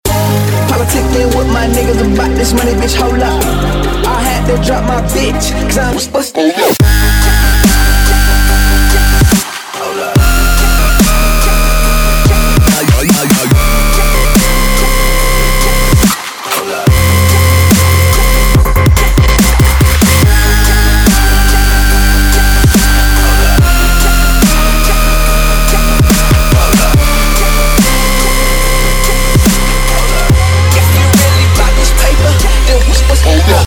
• Качество: 192, Stereo
Мощный дабстеп ремикс